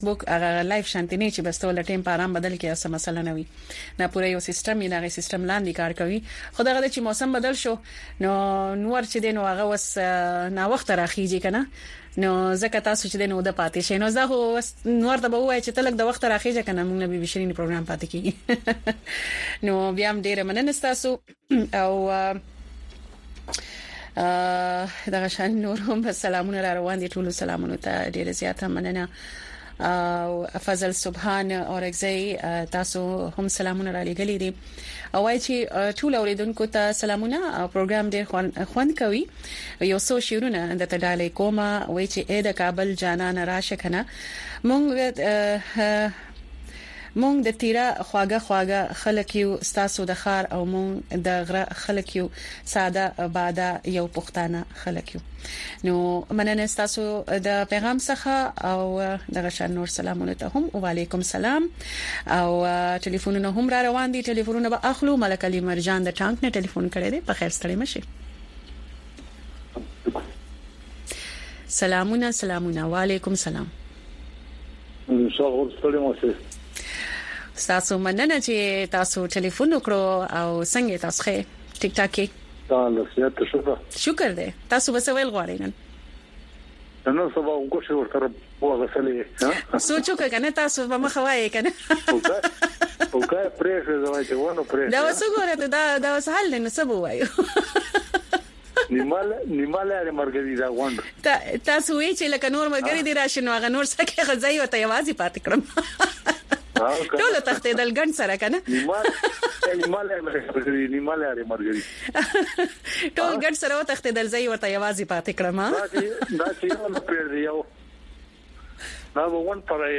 په دې دوؤ ساعتو پروگرام کې تاسو خبرونه او د هغې وروسته، په یو شمېر نړیوالو او سیمه ایزو موضوگانو د میلمنو نه پوښتنې کولی شۍ.